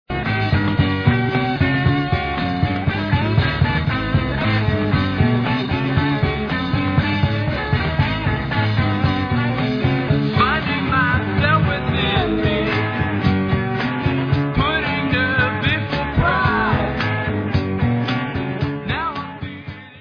Sixties psychedelia, could be on the "nuggets" box